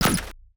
UIClick_Menu Laser Hit Rustle Tail 02.wav